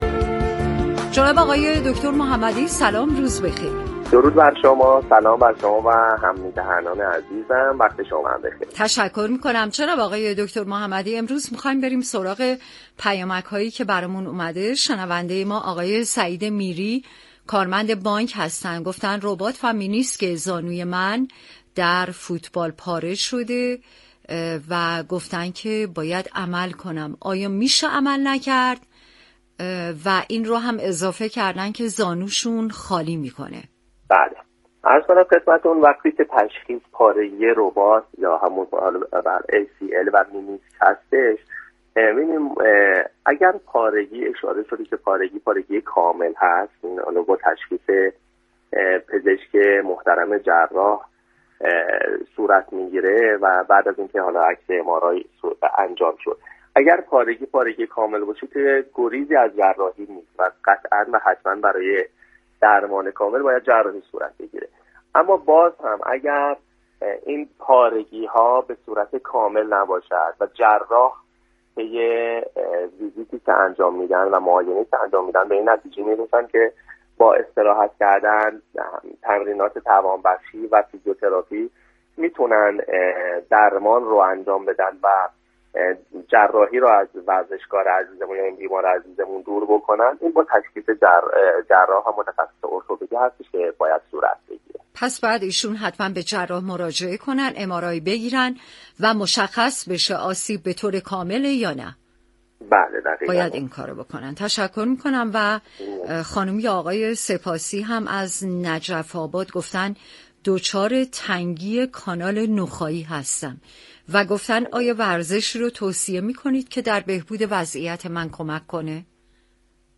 در گفت وگو با برنامه نسخه ورزشی رادیو ورزش.